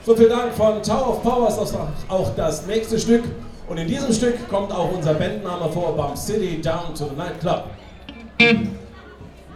23 - Ansage.mp3